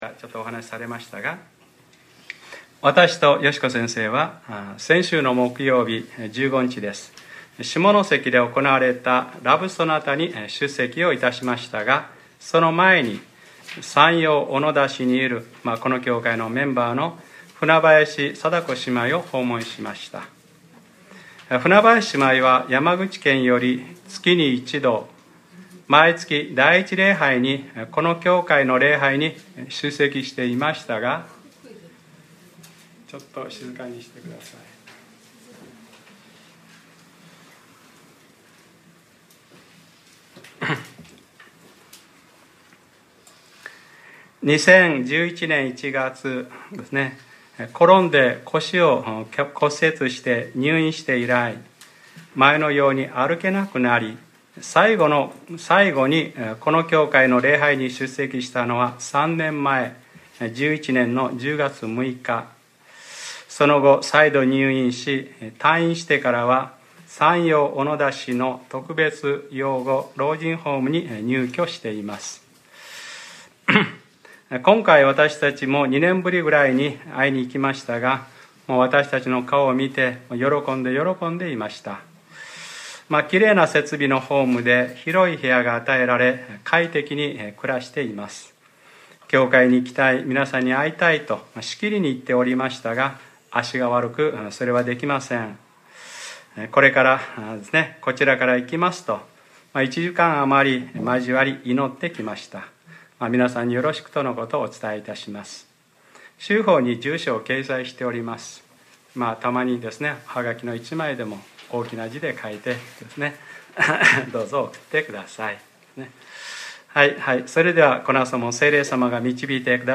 2014年 5月18日（日）礼拝説教『ルカ-４０：主の祈り』